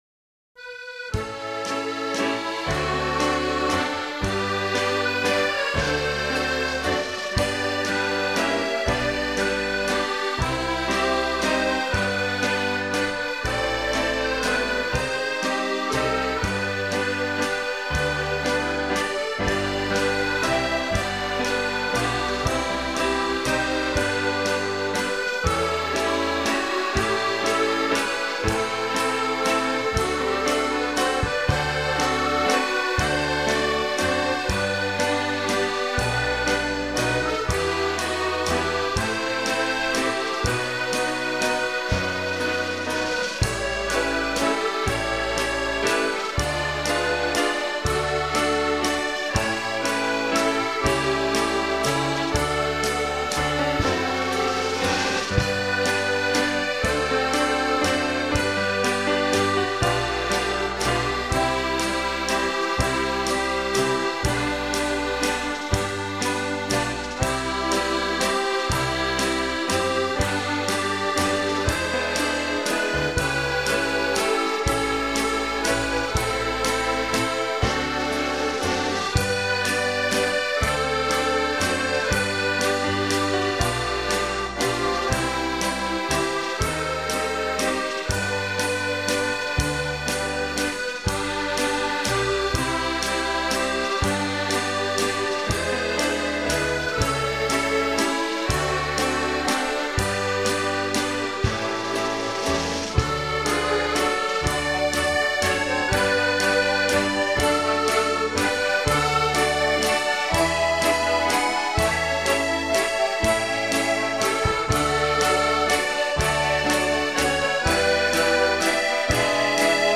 Genre: Scottish.